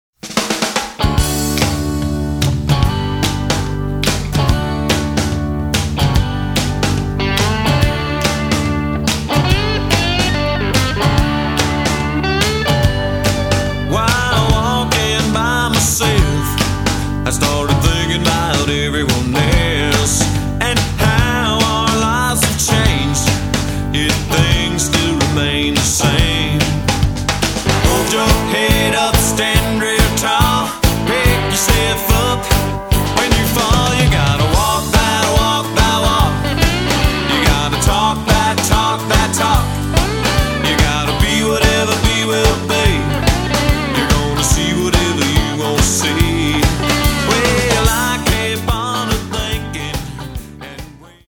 a country/rock record with a little edge.